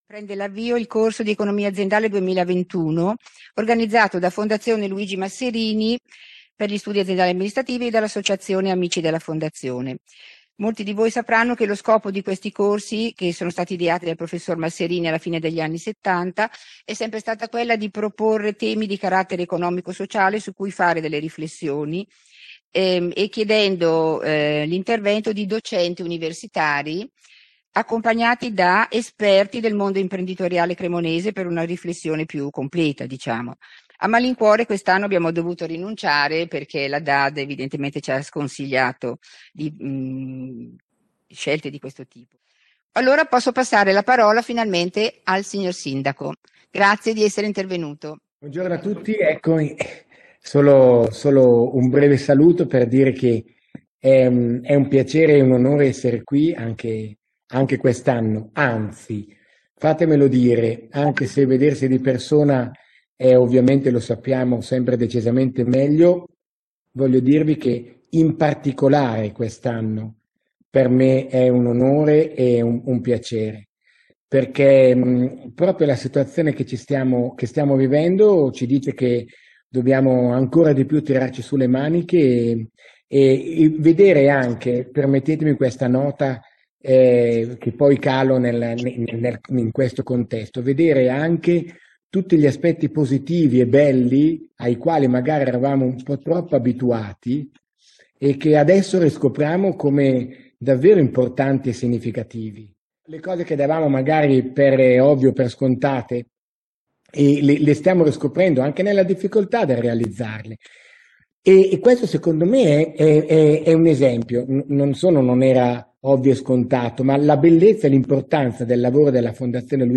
Corso Monografico di Economia Aziendale
Prof. Gianluca GalimbertiSindaco di Cremona